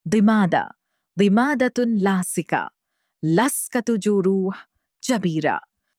چسب زخم به عربی: آشنایی با کلمات و کاربردها (+تلفظ) - گاما تبریز
band-aid-in-arabic.mp3